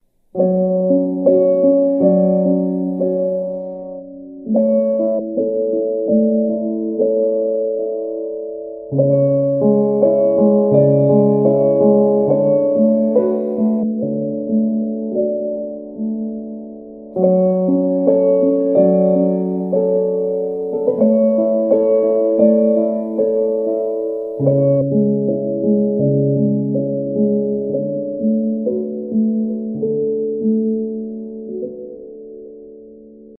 some sad music for a sound effects free download